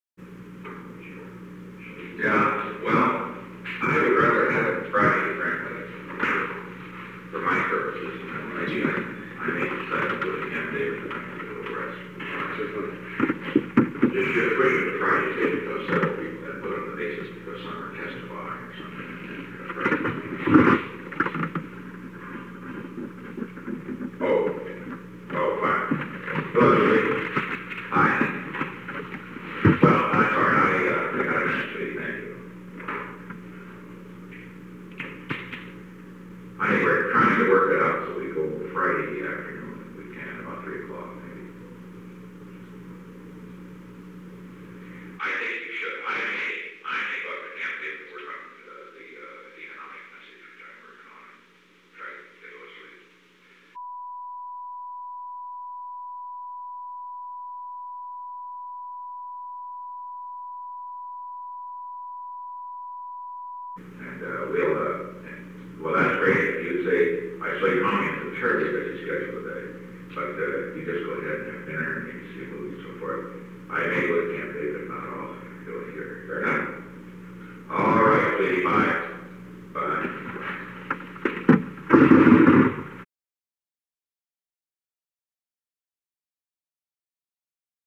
Conversation: 900-024
Recording Device: Oval Office
The Oval Office taping system captured this recording, which is known as Conversation 900-024 of the White House Tapes.
The President talked with Julie Nixon Eisenhower between 2:57 pm and 2:58 pm.
[Begin telephone conversation]